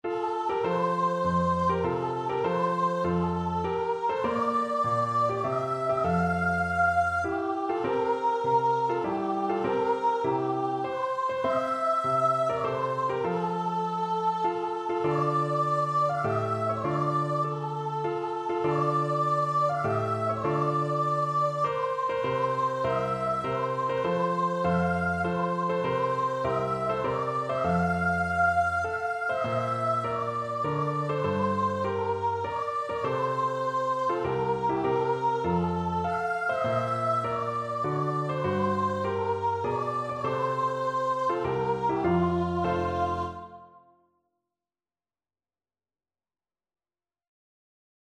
Voice
F major (Sounding Pitch) (View more F major Music for Voice )
3/4 (View more 3/4 Music)
Classical (View more Classical Voice Music)
cyprus_nat_VOICE.mp3